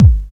3709L BD.wav